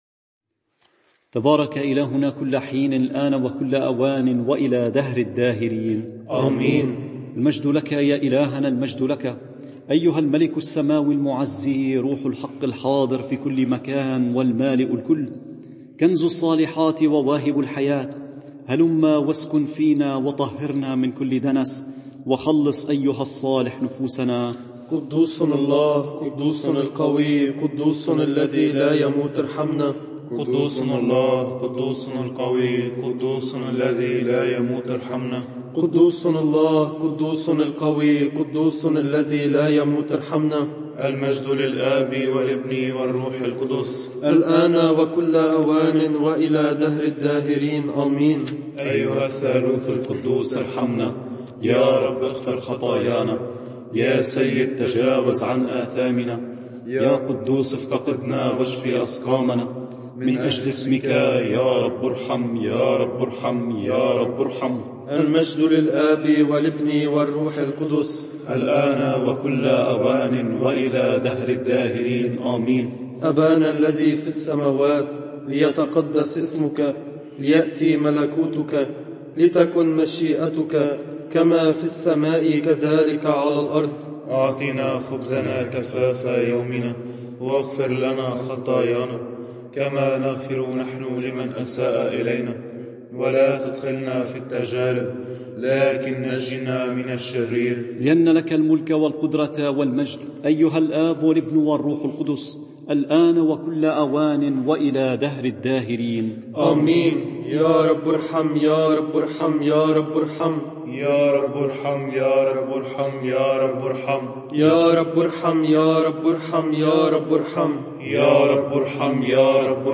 صلاة الساعة الثالثة رهبان
صلاة الساعة الثالثة رهبان.mp3